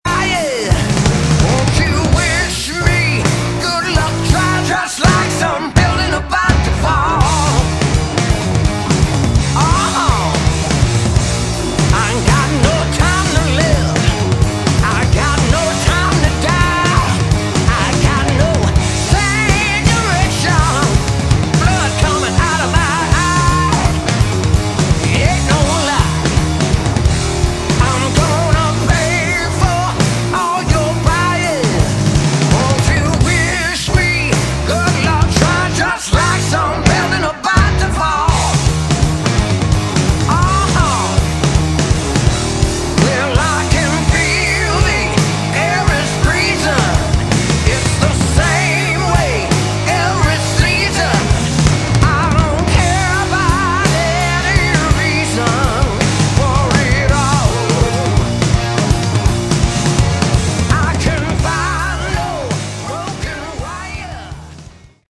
Category: Melodic Rock
bass
vocals
drums
guitars